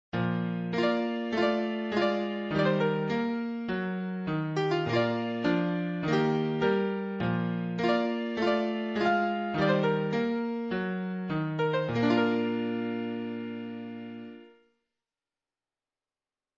TRADITIONAL MECHANICAL MUSIC BOX